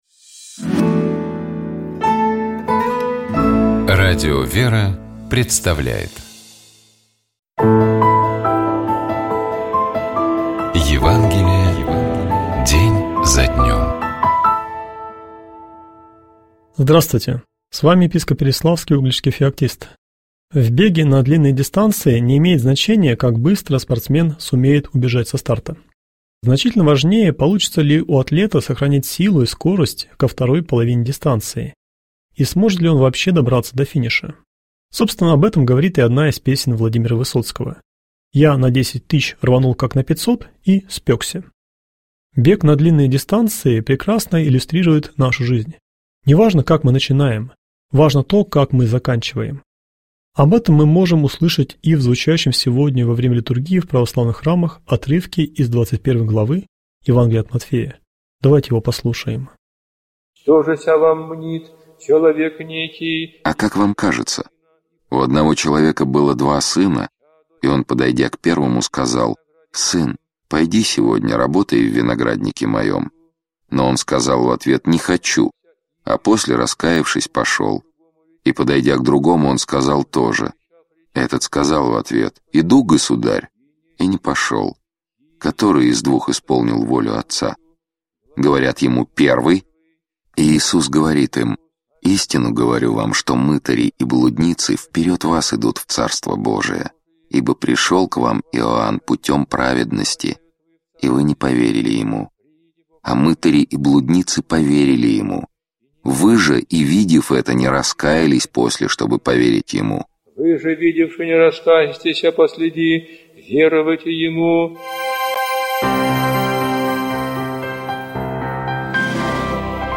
Читает и комментирует
епископ Переславский и Угличский Феоктист